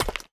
Free Fantasy SFX Pack
Chopping and Mining
mine 3.wav